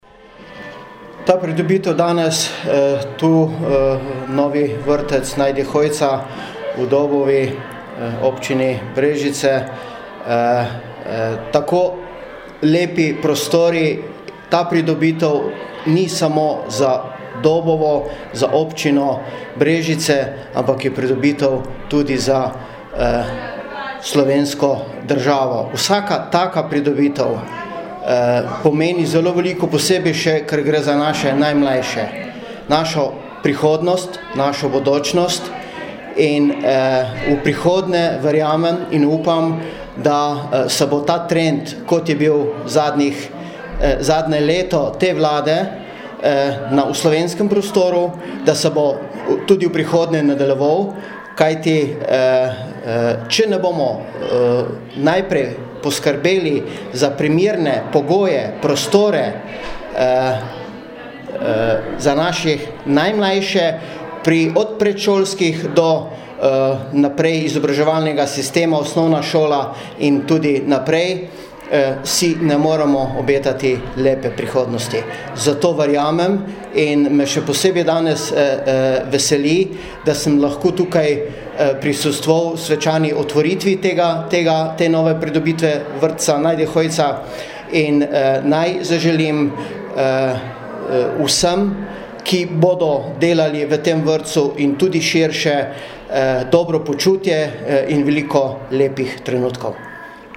izjava_dra3_4avni_sekretar_marjan_dolina_ek.mp3 (2,4MB)